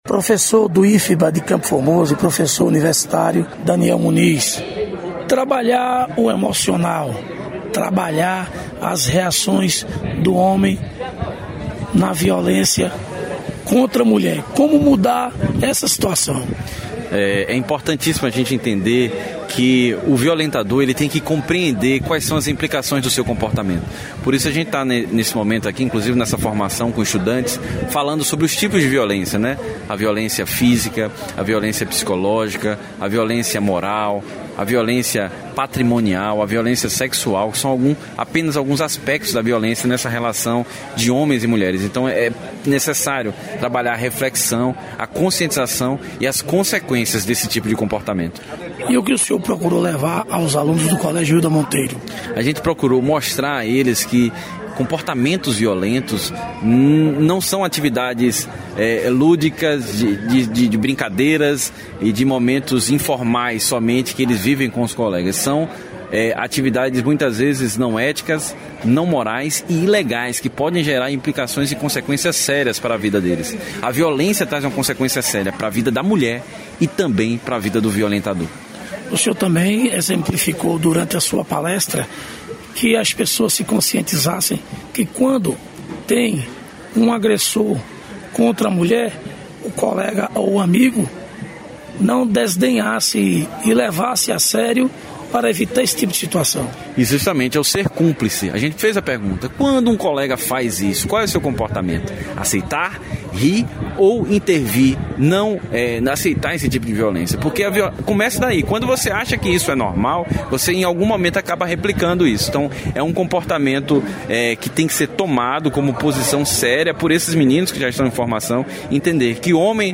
Palestra em colégio estadual de CFormoso abordando a conscientização do respeito à mulher.